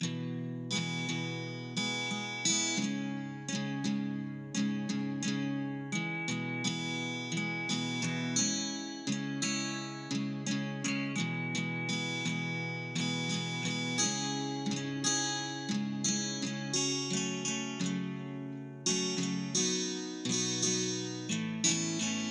老虎伍兹原声吉他86
描述：原声吉他和弦进展，开放性弦乐
Tag: 86 bpm Soul Loops Guitar Acoustic Loops 3.76 MB wav Key : E